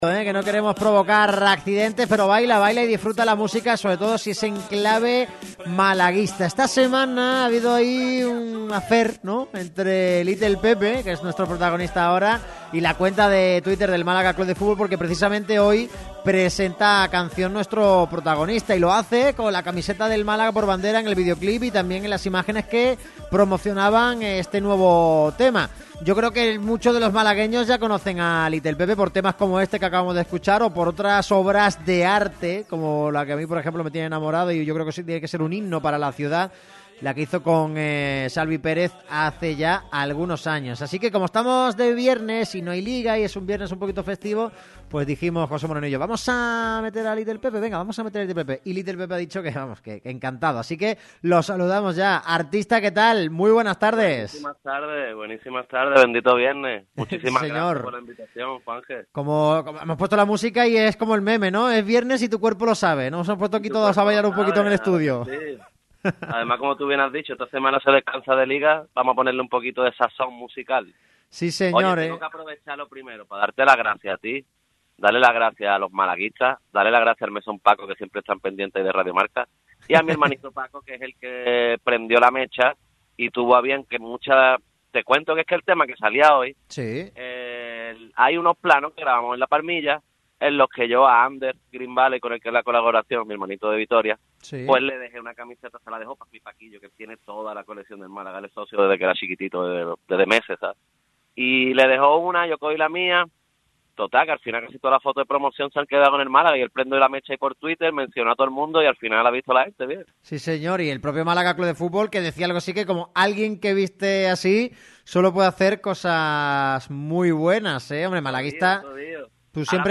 pasó por los micrófonos de Radio MARCA Málaga.